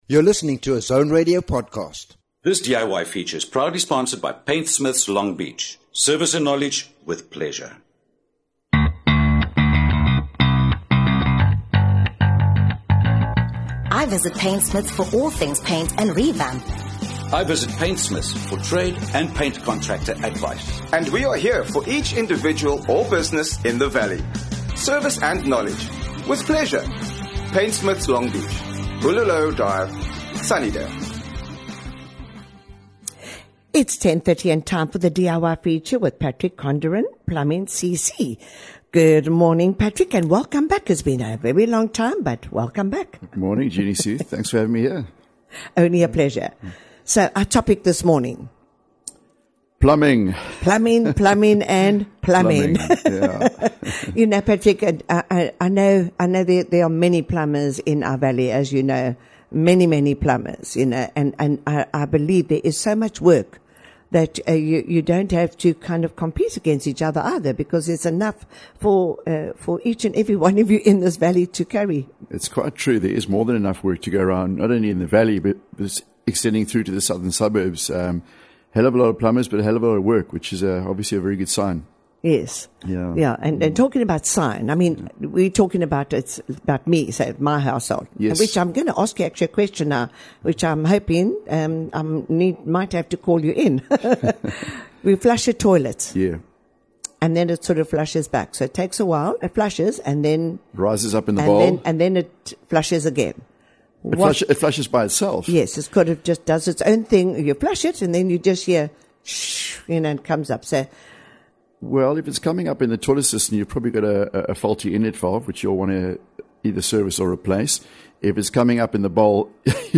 This interview is sponsored by Paintsmiths Longbeach.